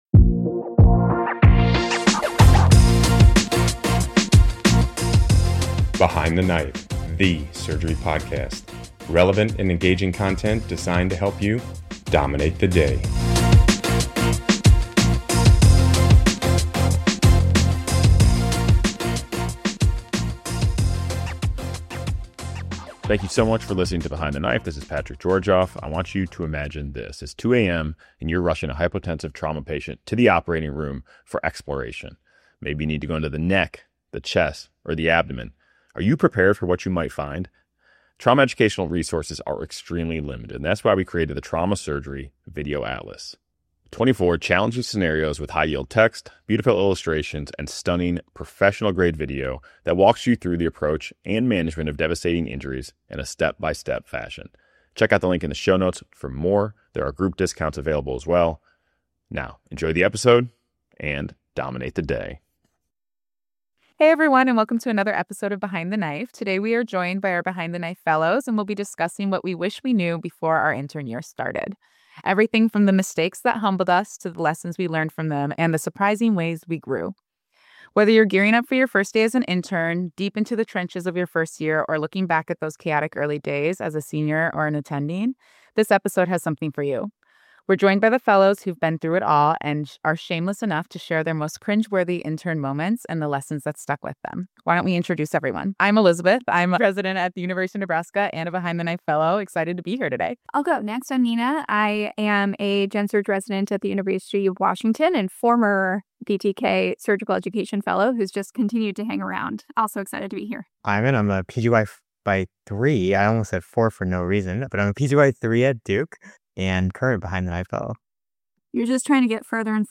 In this episode of Behind the Knife, our surgical education fellows reflect on what they wish they had known before Day 1—and all the humbling, hilarious, and genuinely formative moments along the way. From getting lost wheeling a critical patient through the hospital, to triple-scrubbing just to be acknowledged, to accidentally spraying TPA into your own eye (yes, really)—this episode is a candid conversation about the highs, lows, and everything in between.